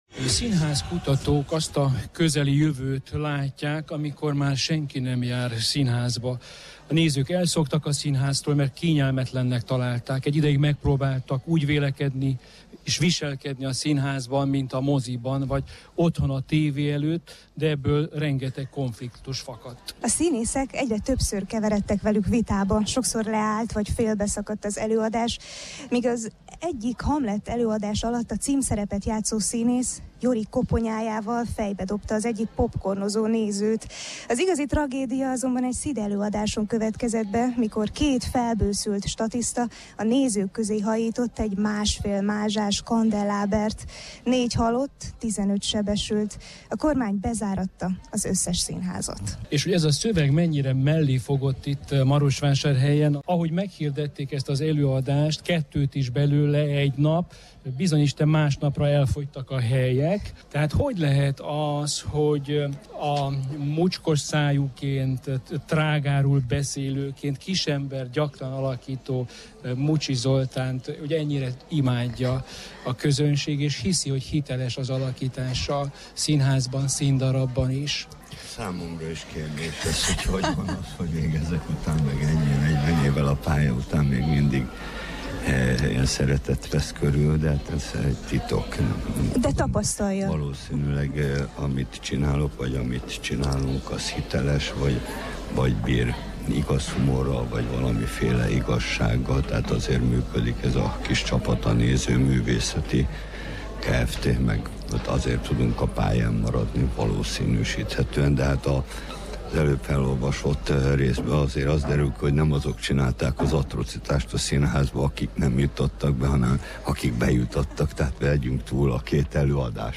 Előtte pedig Mucsi Zoltán és Katona László a Marosvásárhelyi Rádió Kultúrpresszó című műsorának vendégei voltak!